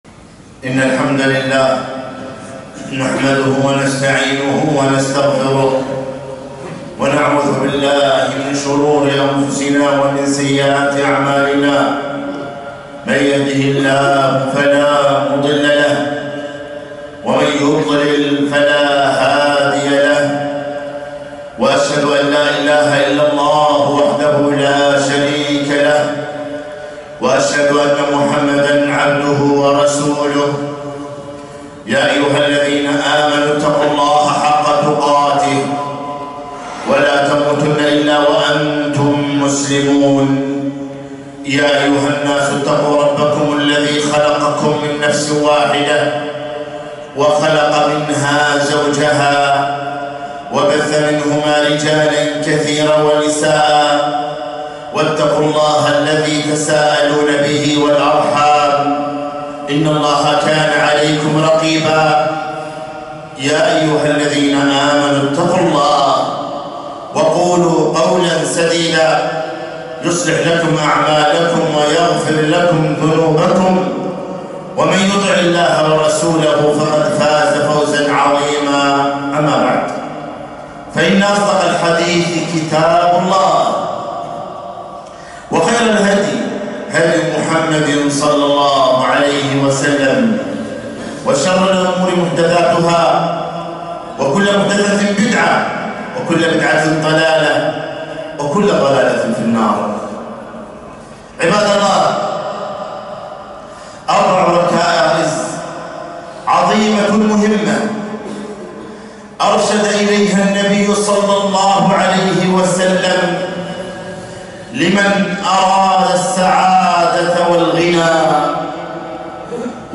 خطبة - أربع إذا كن فيك فلا عليك ما فاتك من الدنيا